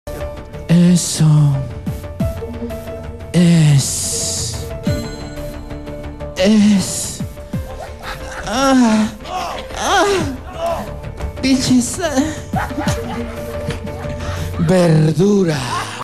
voz-en-off-aguantada-el-especial-del-humor.mp3